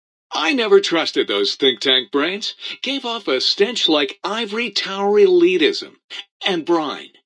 Category: Old World Blues audio dialogues Du kannst diese Datei nicht überschreiben.